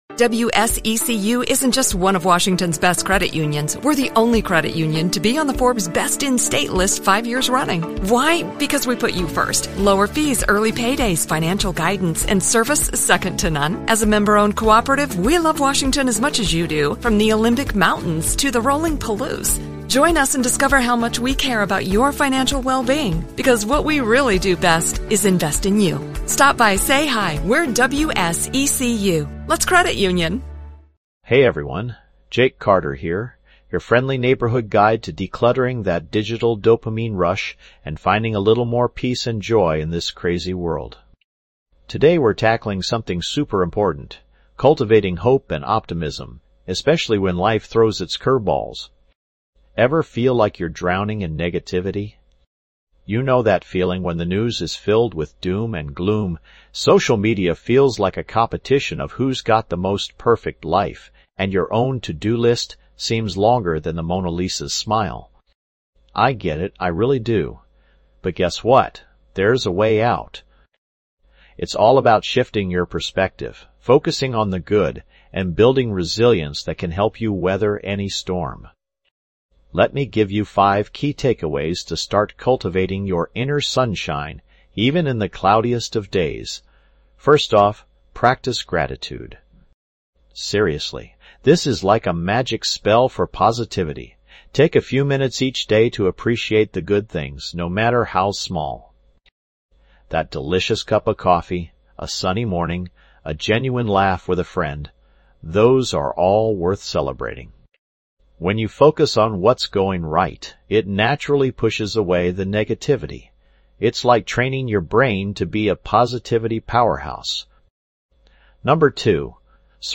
Personal Development, Mental Health & Wellness, Inspirational Talks
This podcast is created with the help of advanced AI to deliver thoughtful affirmations and positive messages just for you.